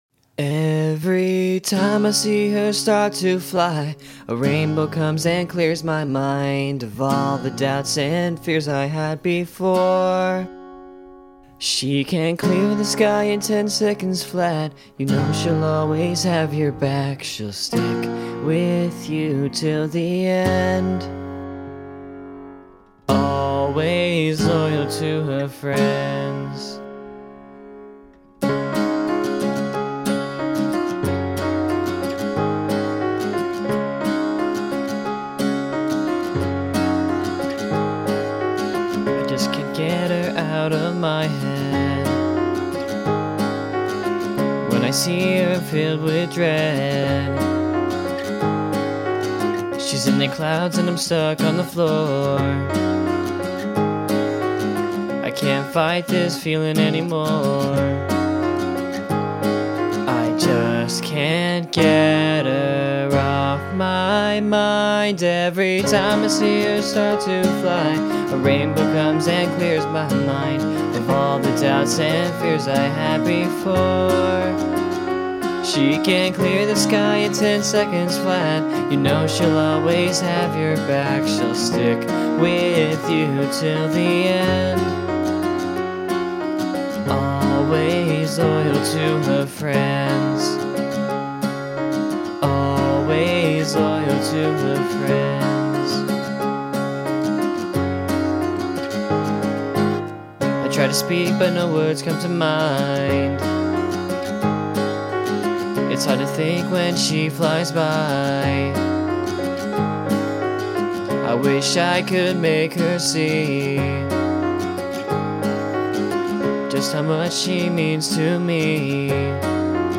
This was originally going to be an alternative-rock-punk kinda song, but acoustic is good too right?
Another fun fact for you, this was also supposed to be half step down, instead I recorded it 2 steps up!